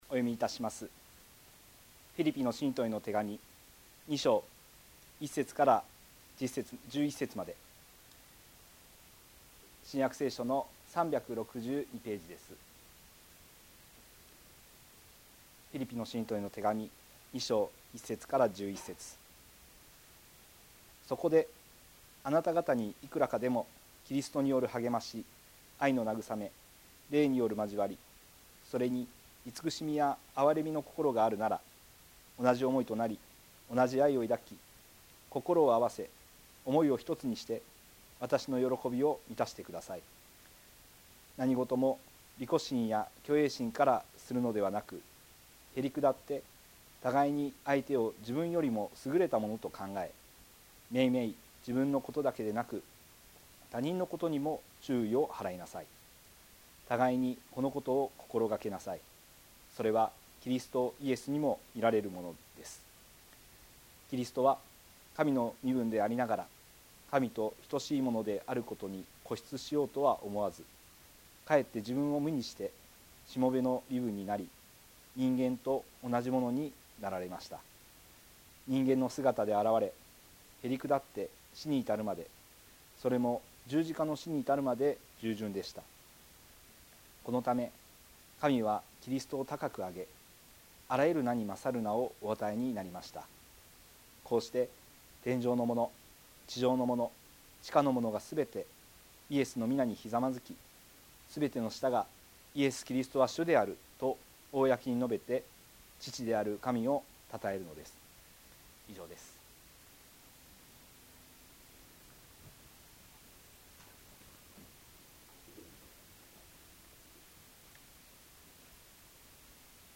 説教アーカイブ。
ホームページで礼拝説教の音声データを公開しています。